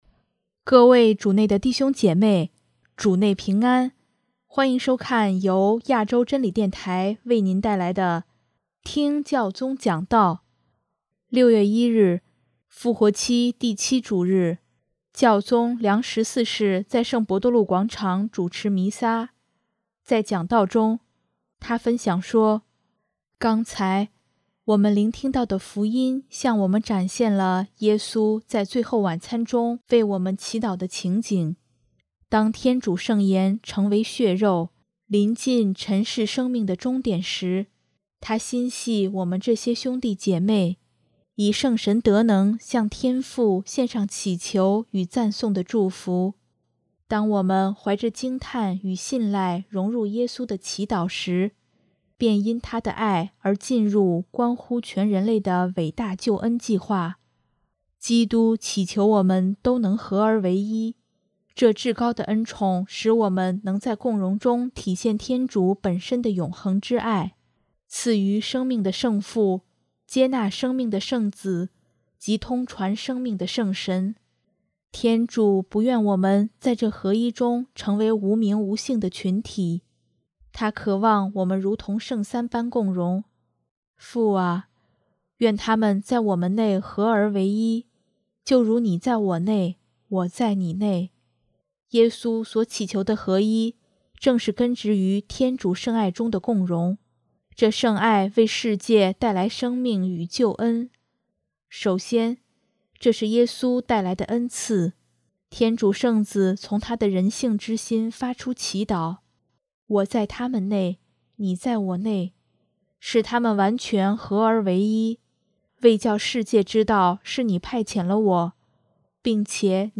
6月1日，复活期第七主日，教宗良十四世在圣伯多禄广场主持弥撒，在讲道中，他分享说：